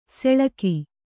♪ seḷaki